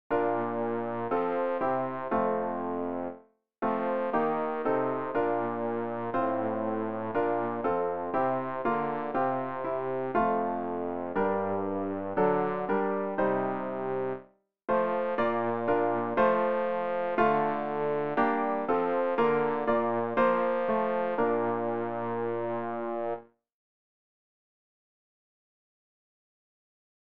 Übehilfen für das Erlernen von Liedern
rg-687-gott-ist-mein-hort-bass.mp3